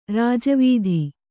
♪ rāja vīdhi